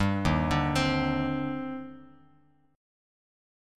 EbM13 chord